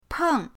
peng4.mp3